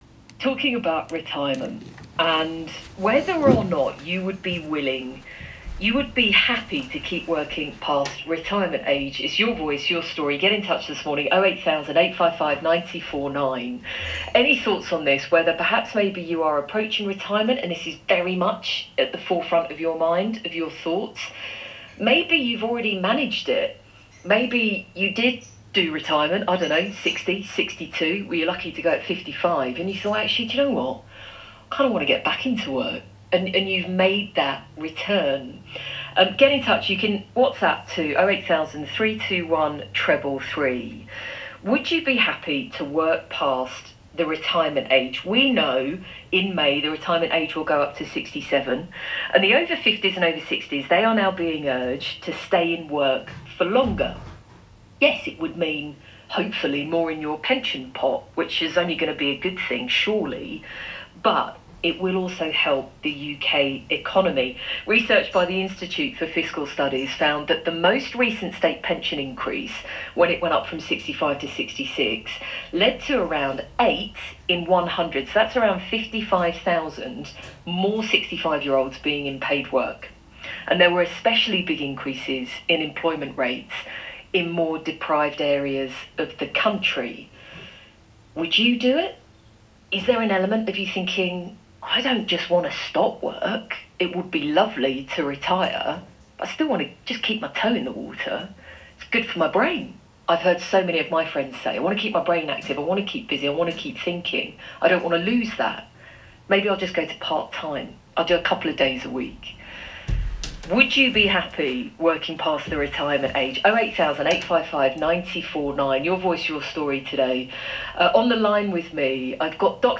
This morning I was on BBC Bristol talking about the plan to raise the state pension age to 67 and the push to keep more people in work beyond 55 and 65.
BBC-Radio-Bristol.m4a